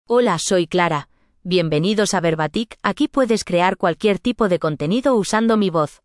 ClaraFemale Spanish AI voice
Clara is a female AI voice for Spanish (Spain).
Voice sample
Listen to Clara's female Spanish voice.
Female
Clara delivers clear pronunciation with authentic Spain Spanish intonation, making your content sound professionally produced.